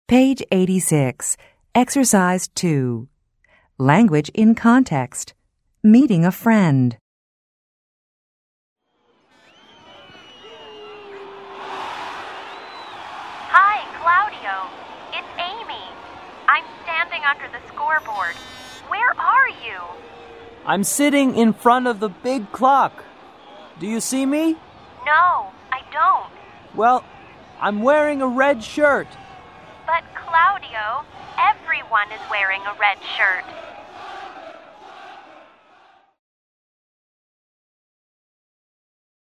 American English